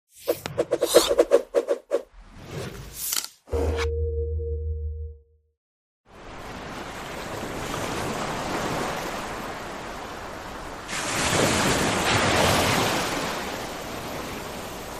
Waves Crashing
Waves Crashing is a free nature sound effect available for download in MP3 format.
291_waves_crashing.mp3